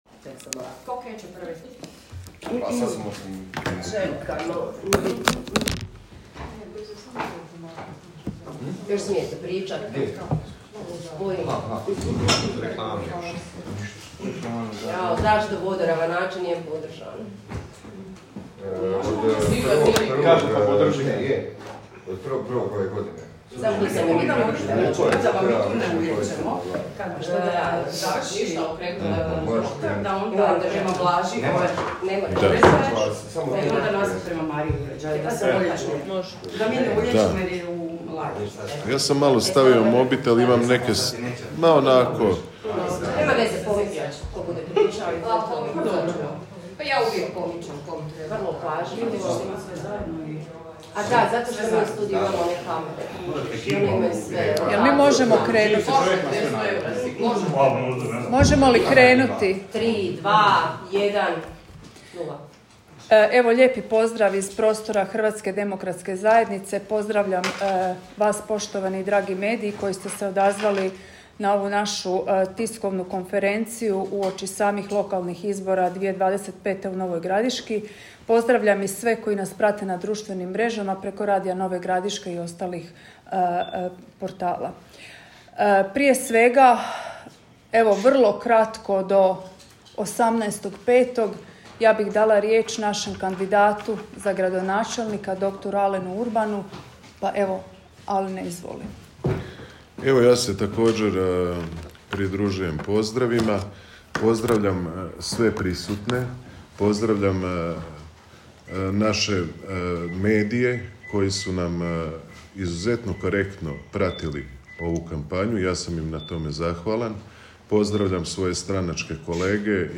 Danas je u prostorijama Hrvatske demokratske zajednice održana konferencija za medije
SNIMAK-KONFERENCIJE.m4a